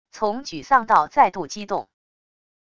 从沮丧到再度激动wav音频